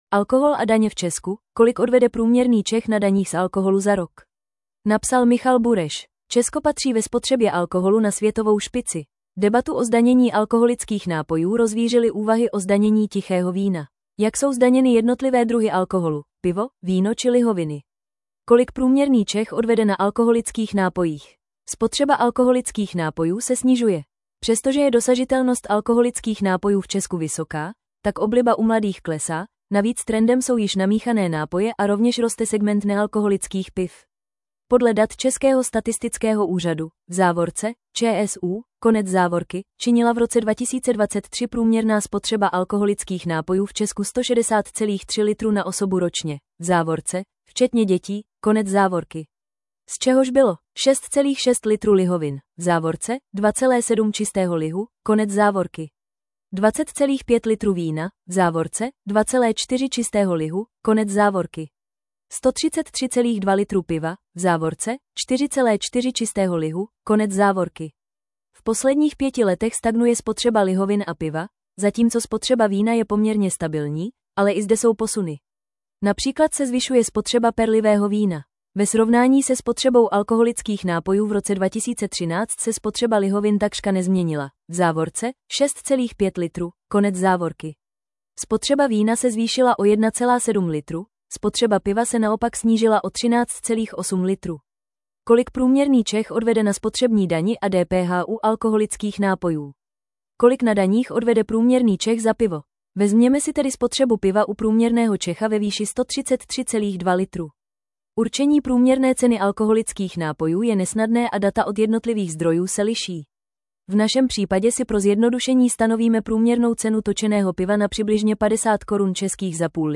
Rychlost přehrávání 0,5 0,75 normální 1,25 1,5 Poslechněte si článek v audio verzi 00:00 / 00:00 Tento článek pro vás načetl robotický hlas.